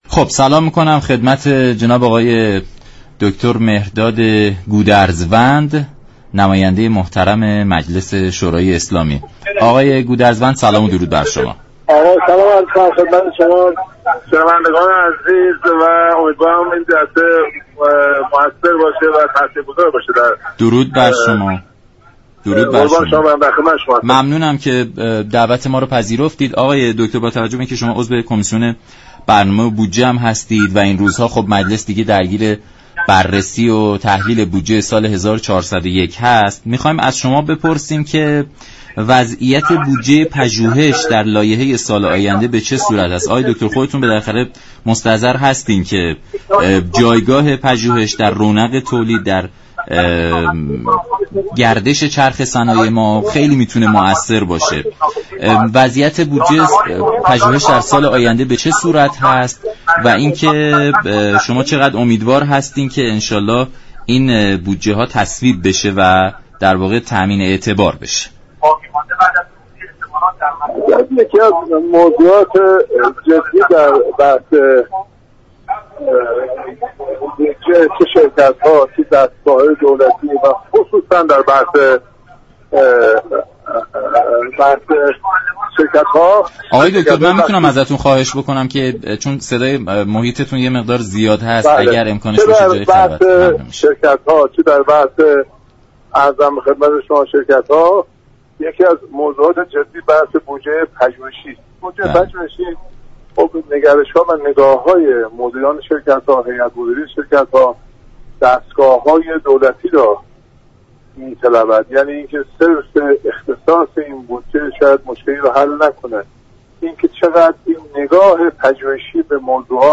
به گزارش شبكه رادیویی ایران، دكتر مهرداد گودرزوند نماینده مجلس و عضو كمیسیون برنامه و بودجه مجلس در برنامه «نمودار» به جایگاه پژوهش در كشور گفت: بودجه پژوهشی، نگرش و نگاه های مدیران شركت ها و دستگاه های دولتی را می طلبد اینكه فقط بودجه ای به این حوزه اختصاص داده شود مشكل را حل نمی كند نگاه پژوهشی مدیران به موضوعات است كه باعث تغییر و تحول در این حوزه می شود.